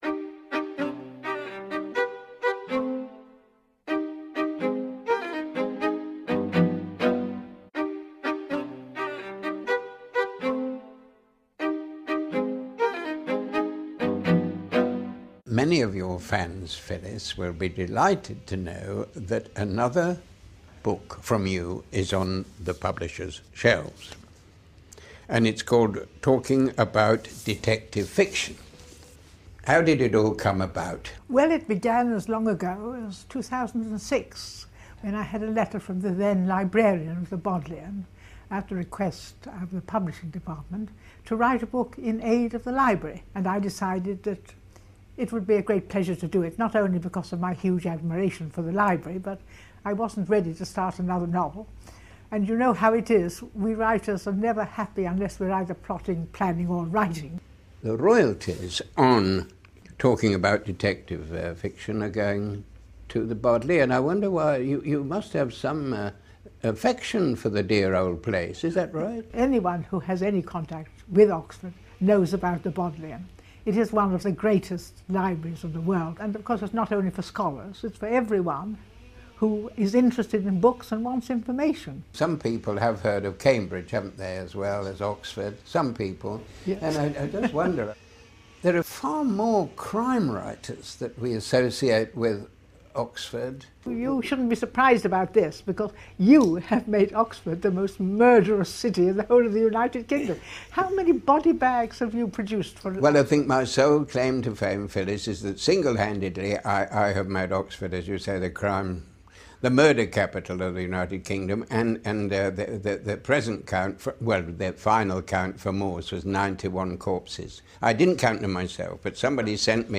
BODcast: P.D. James in conversation with Colin Dexter (long)